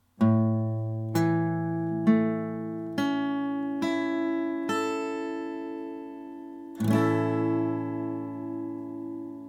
A-Dur (Barré, E-Saite)
A-Dur Barre (E-Saite, Gitarre)
A-Dur-Barre-E.mp3